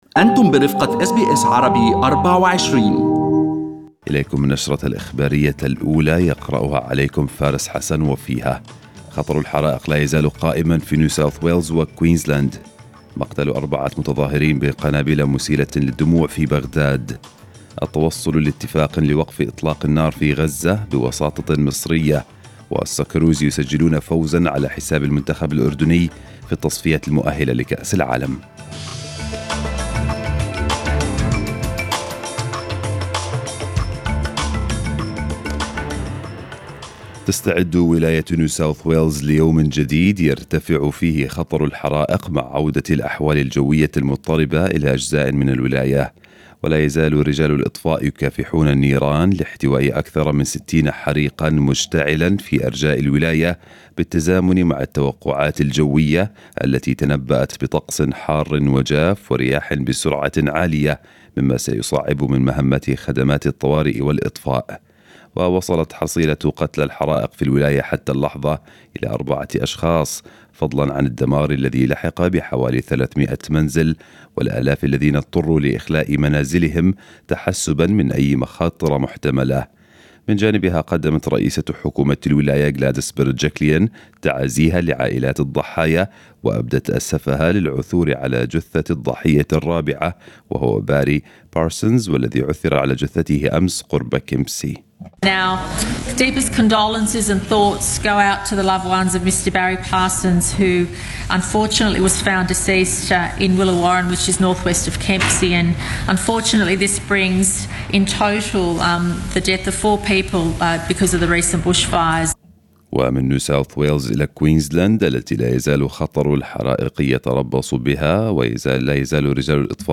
خطر الحرائق لا يزال يتربص بنيو ساوث ويلز وكوينزلاند في نيو ساوث ويلز وكوينزلاند ومقتل أربعة متظاهرين بقنابل مسيلة للدموع في بغداد وأخبار متفرقة في النشرة الإخبارية.